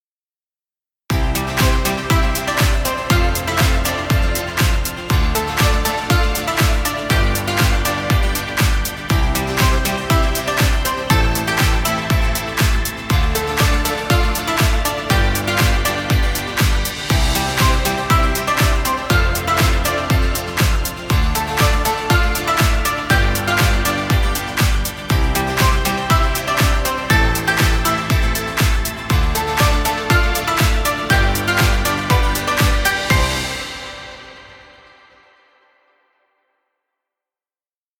Happy motivational music. Background music Royalty Free.